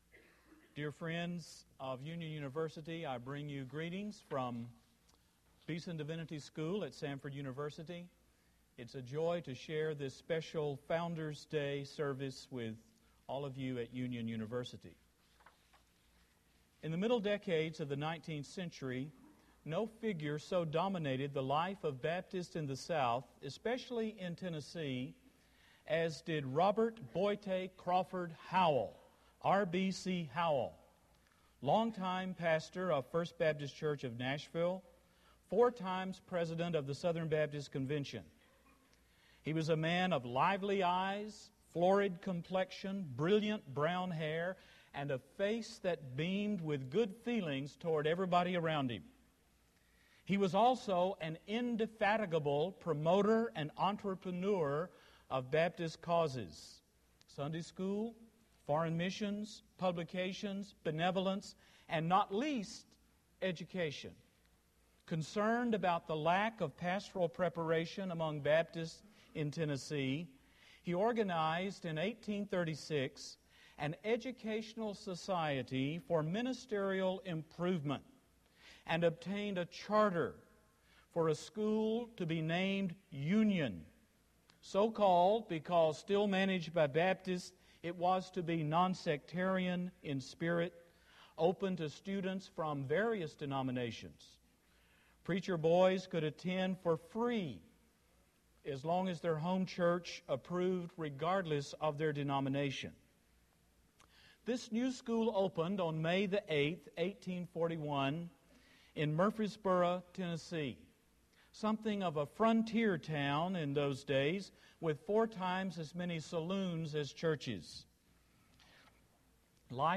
Founders' Day Chapel
Address: The Legacy of Landmarkism and Union University Recording Date